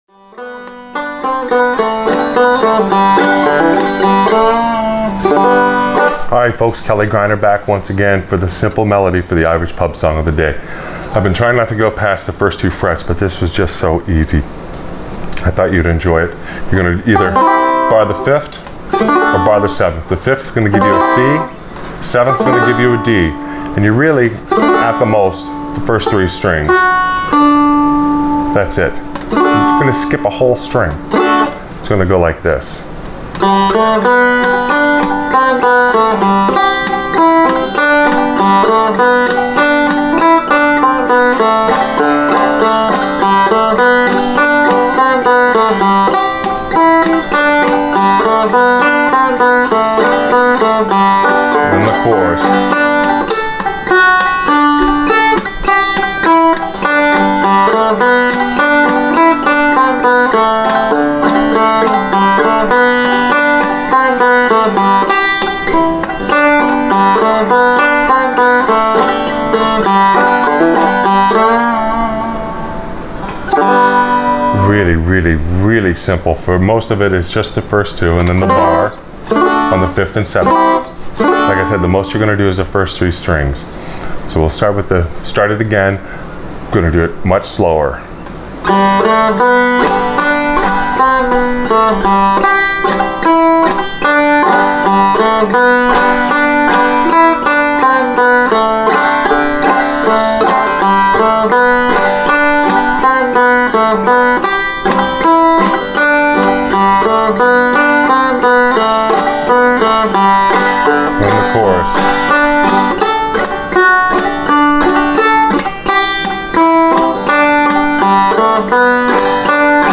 Irish Pub Song Of The Day – The Leaving Of Liverpool – Simple Melody on Frailng Banjo